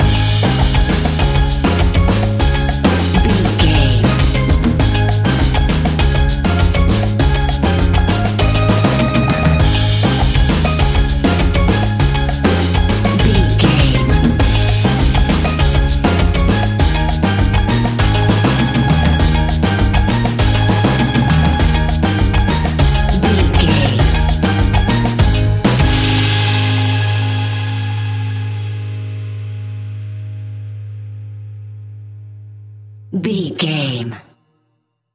Ionian/Major
A♭
steelpan
drums
percussion
bass
brass
guitar